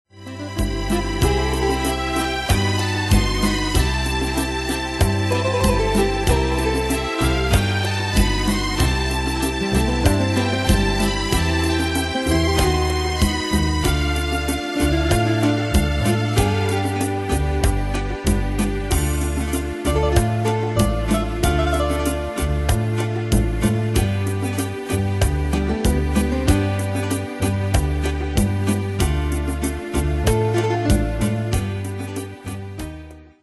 Style: Latino Ane/Year: 1997 Tempo: 95 Durée/Time: 3.12
Danse/Dance: Rhumba Cat Id.
Pro Backing Tracks